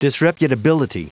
Prononciation du mot disreputability en anglais (fichier audio)
Prononciation du mot : disreputability